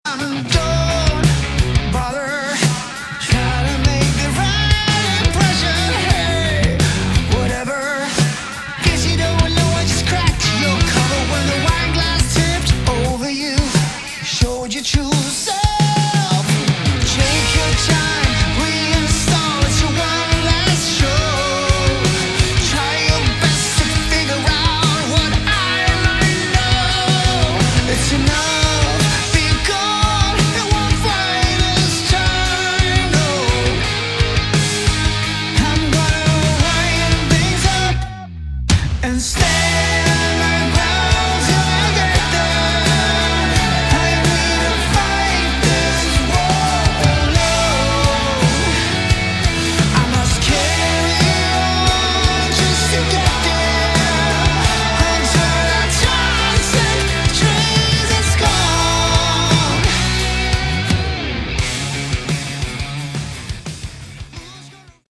Category: Melodic Hard Rock
guitars
bass, vocals
drums